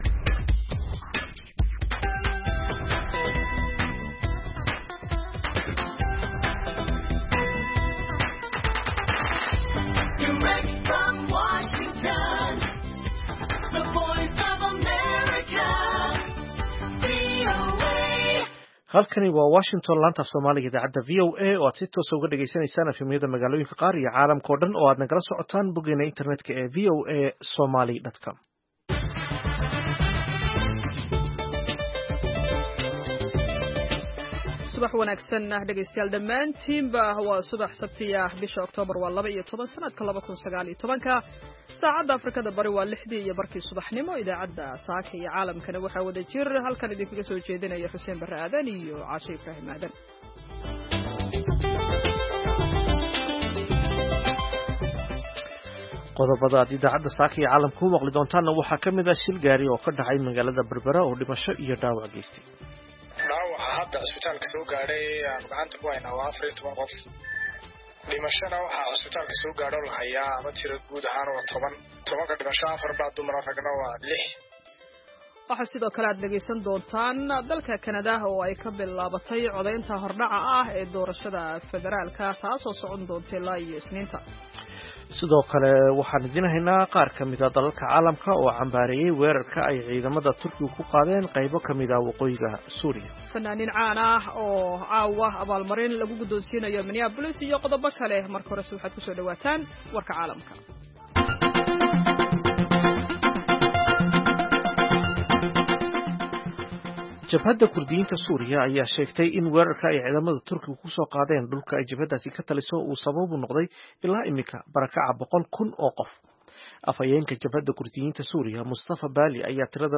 Idaacadda Subaxnimo ee Saaka iyo Caalamka waxaad ku maqashaa wararkii habeenimadii xalay ka dhacay Soomaaliya iyo waliba caalamka, barnaamijyo, wareysi xiiso leh, ciyaaraha, dhanbaallada dhagaystayaasha iyo waliba wargeysyada caalamku waxay saaka ku waabariisteen.